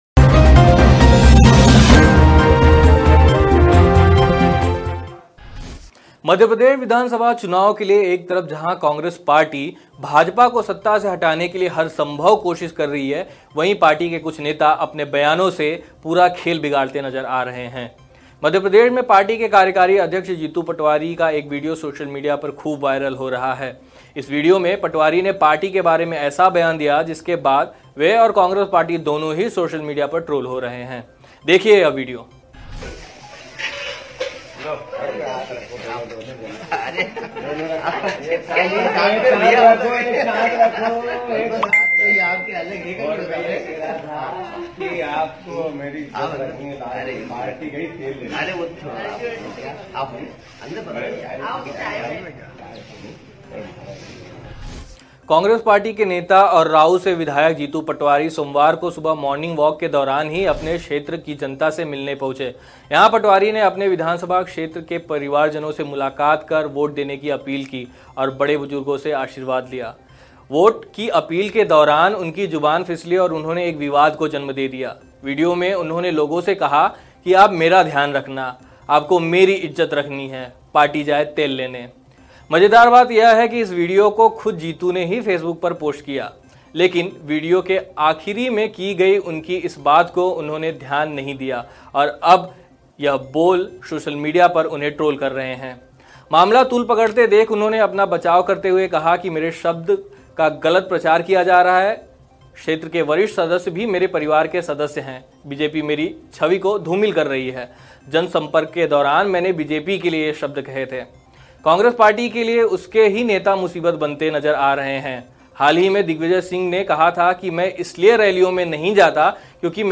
न्यूज़ रिपोर्ट - News Report Hindi / कांग्रेस पार्टी के कार्यकारी अध्यक्ष जीतू पटवारी ने कहा आप मेरा ध्यान रखना, पार्टी जाए तेल लेने